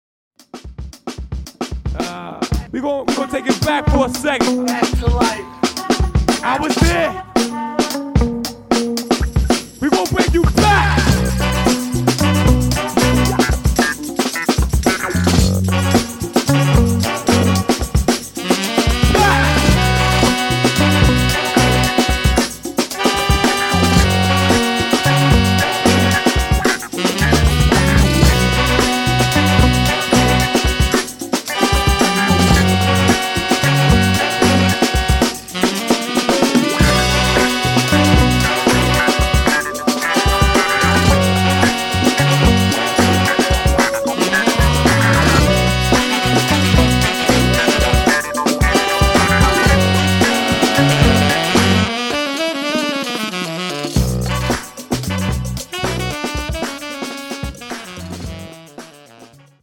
Heavyweight funk instrumentals